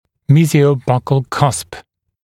[ˌmiːzɪəu’bʌkl kʌsp][ˌми:зиоу’бакл касп]мезиально-щечный бугор (зуба)